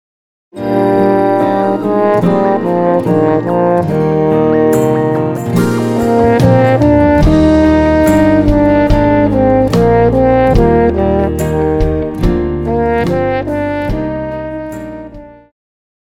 Pop,Christian
French Horn
Band
Traditional (Folk),POP
Instrumental
Only backing